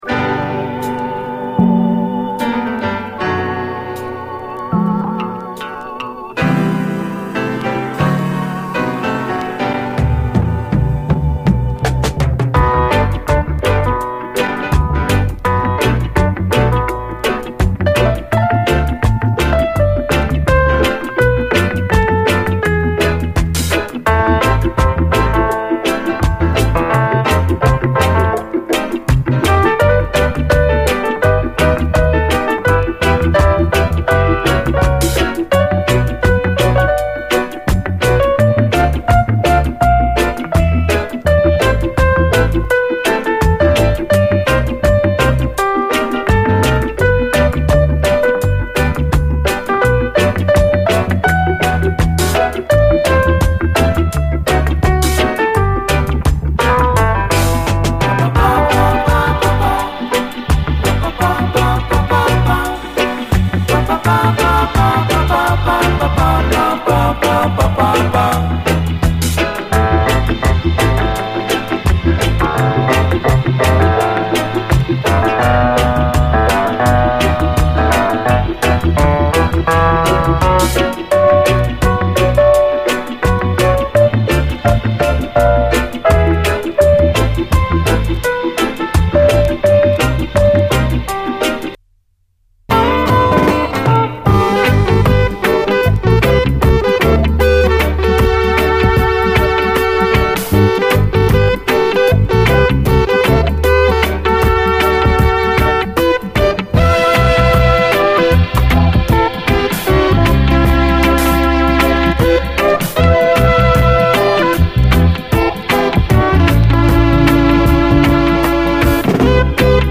REGGAE
全編最高！70'Sジャマイカン・ソウル〜ソウルフル・レゲエの真打ち的名盤！